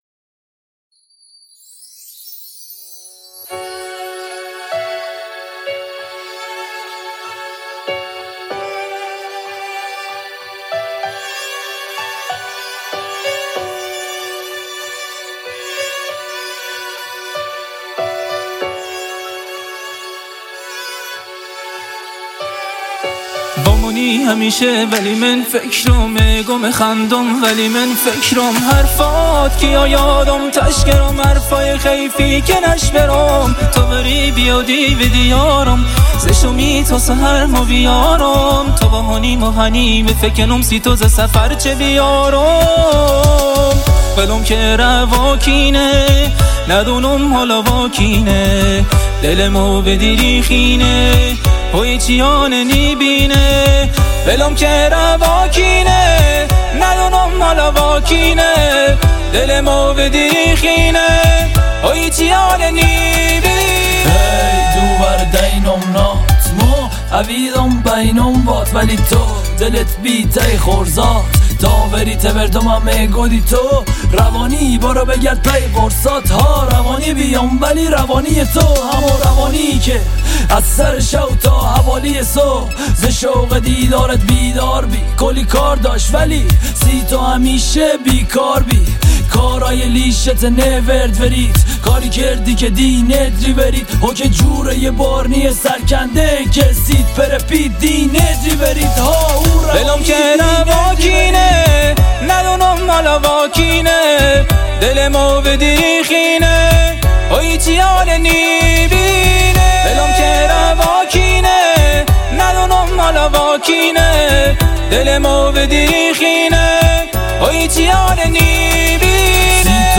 بختیاری میخونن